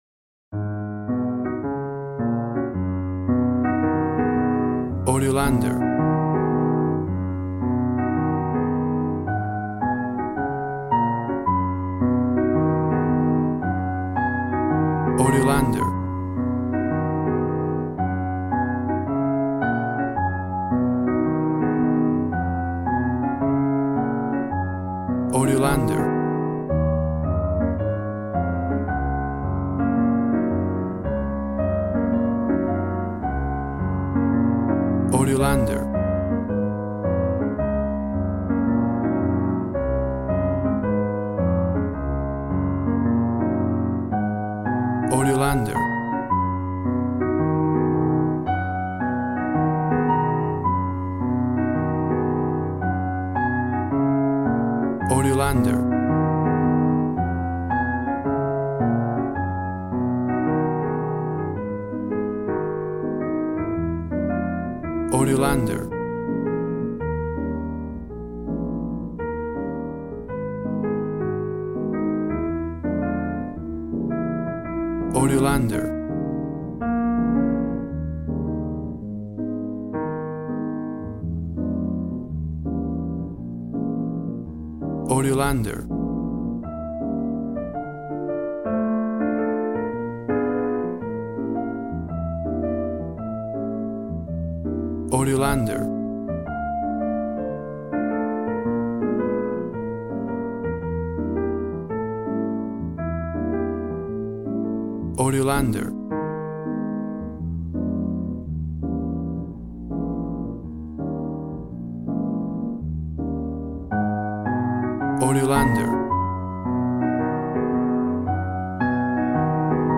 Smooth jazz piano mixed with jazz bass and cool jazz drums.
Tempo (BPM) 107